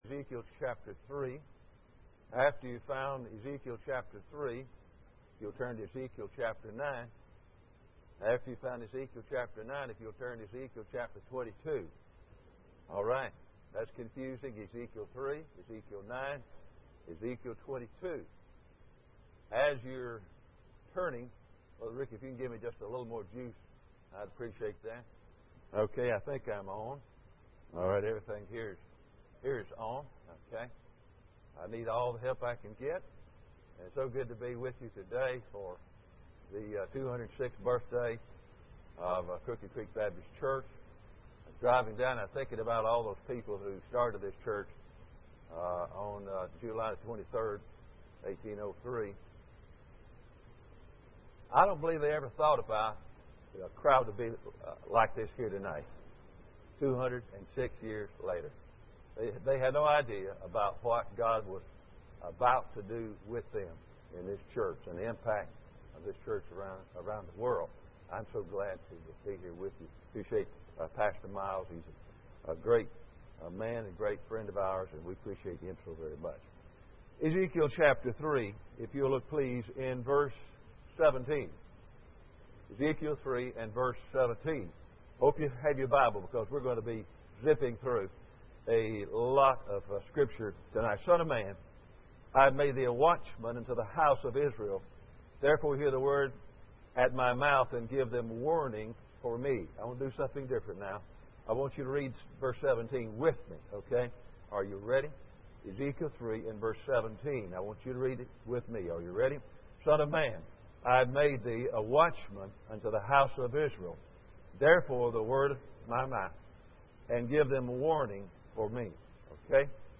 General Service Type: Sunday Evening Preacher